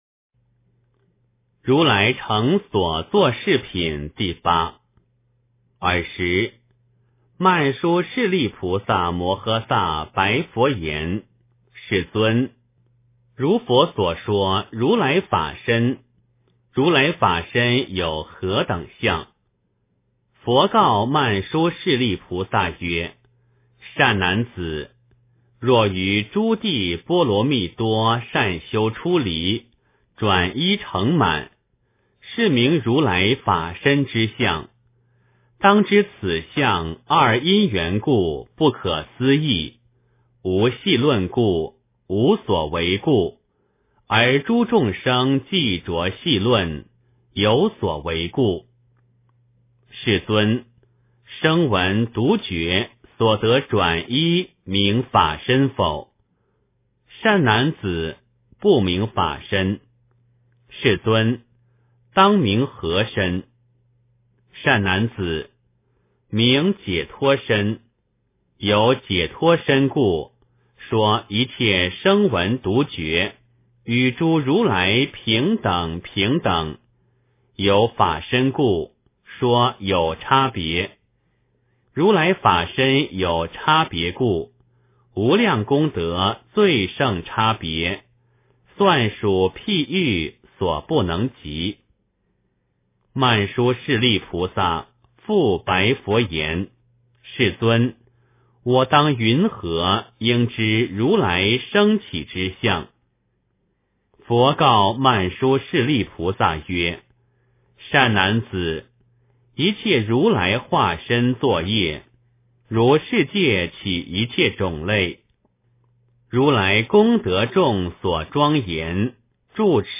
解深密经-8（念诵）